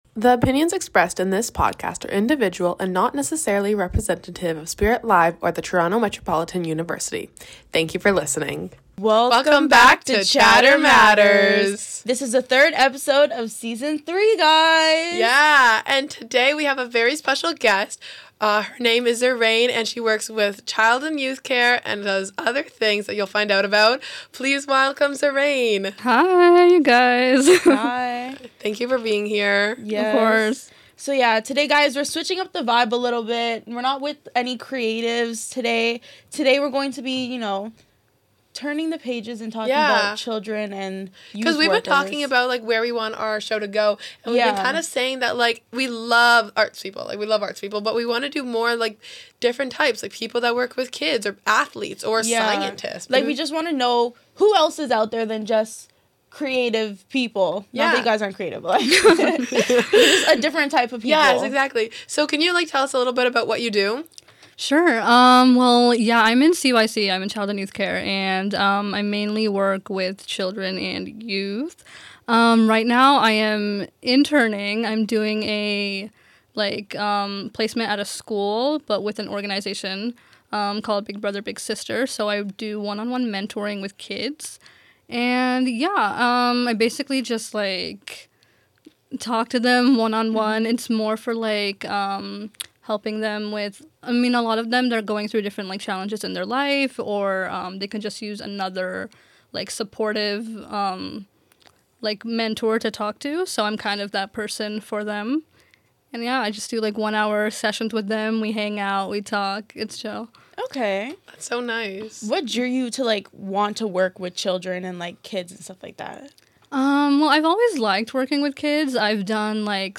Each week get ready for engaging conversations, fresh insights, and plenty of laughs on Chatter Matters!